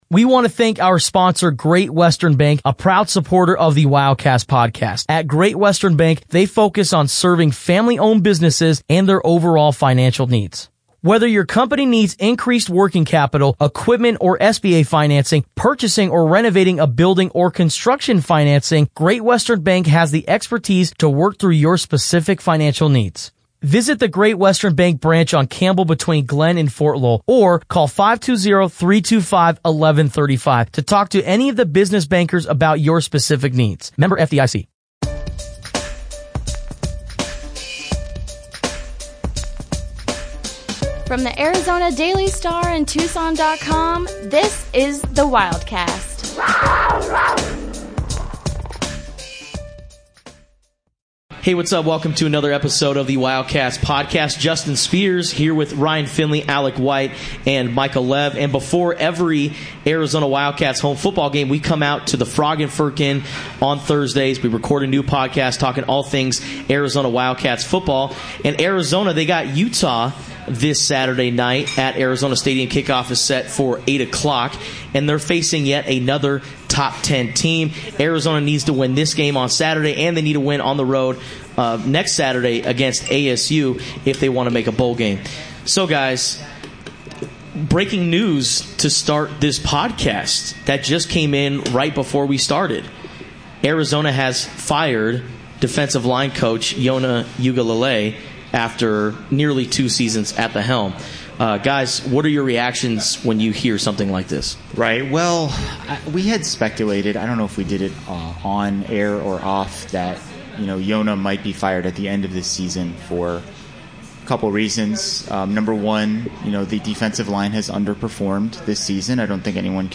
record the latest episode from Frog & Firkin' on University Blvd.